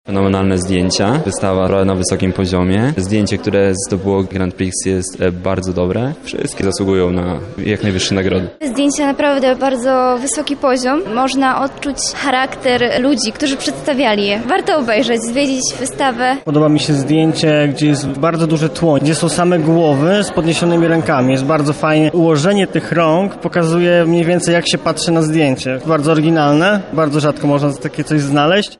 Jak widzowie oceniają teatralne kadry, posłuchajmy: